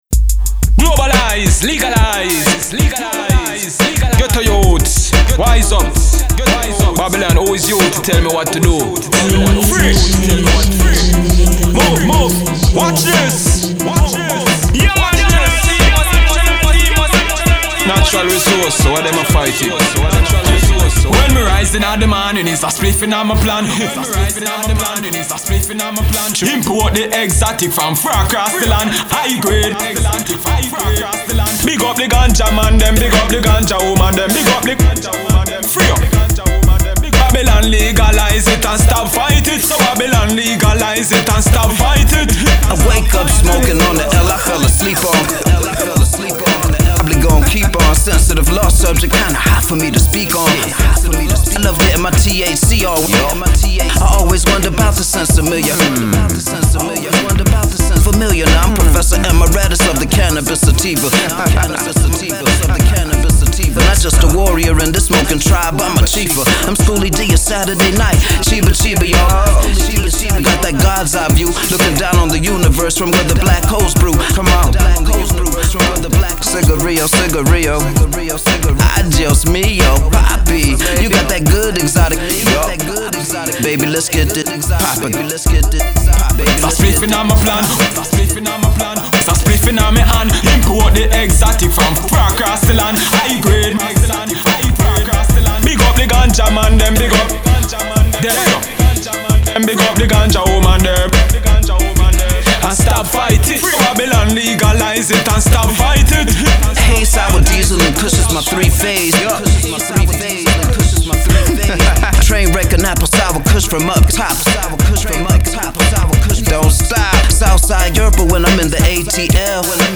heavily Reggae flavored Hip Hop track